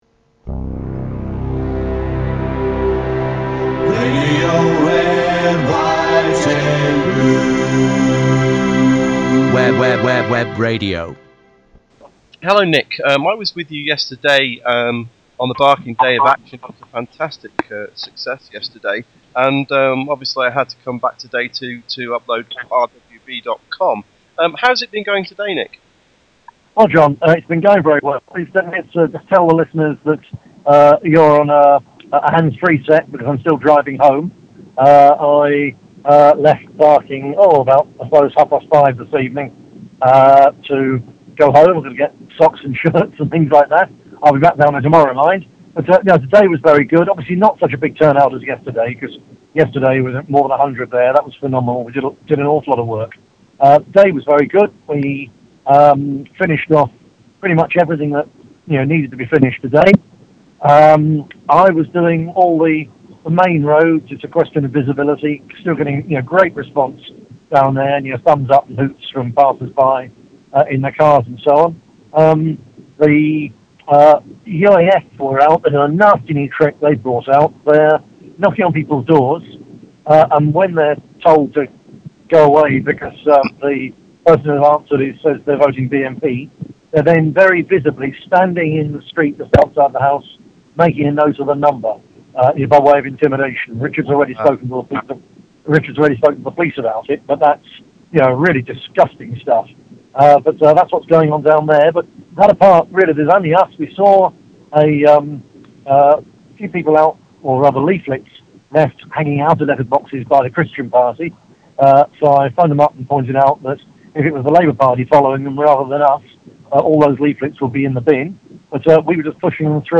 Nick Griffin Interview
NGLIVE.mp3